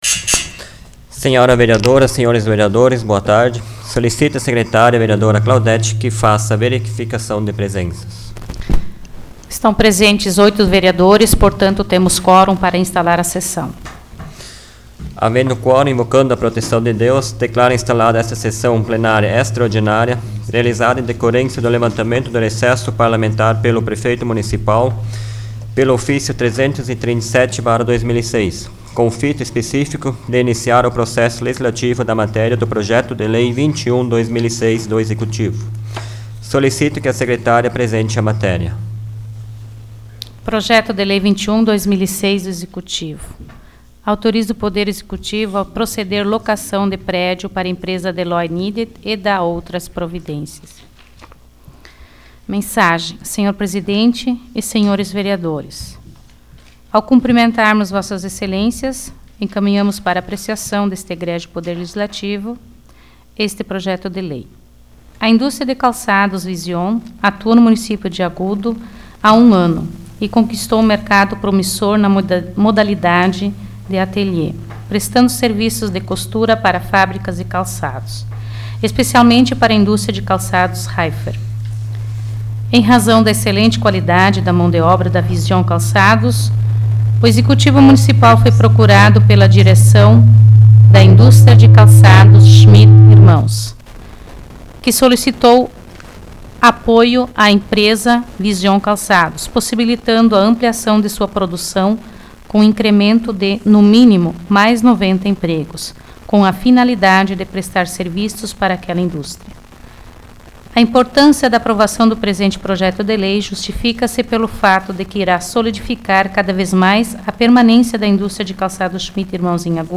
Áudio da 23ª Sessão Plenária Extraordinária da 12ª Legislatura, de 21 de julho de 2006